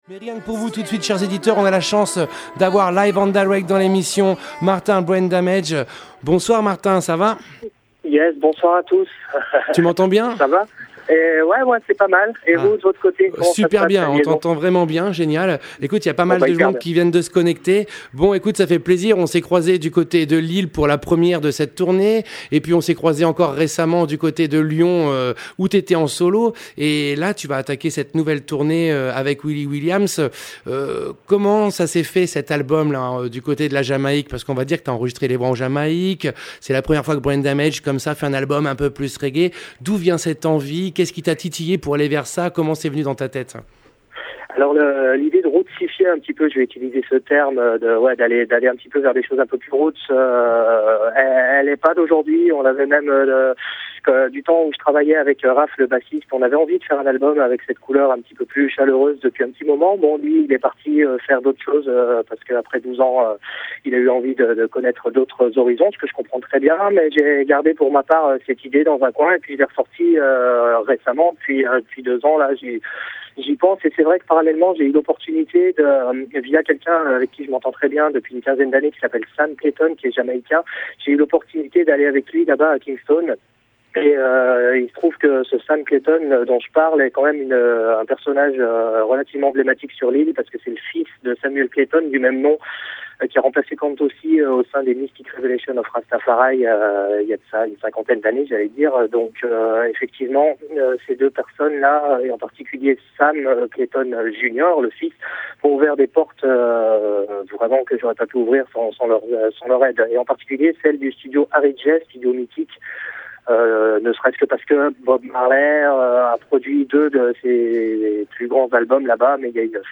Brain Damage – Interview Culture Dub – Radio Pulsar – 13 Octobre 2015
Retrouvez l’interview audio de Brain Damage dans l’émission Culture Dub du 13 Octobre 2015 sur les ondes de Radio Pulsar. Brain Damage nous parle de son album « Walk The Walk » mais aussi de son voyage en Jamaïque, de ses enregistrements avec Ras Michael, Horace Andy, Winston McAnuff, Kiddus I et Willi Williams…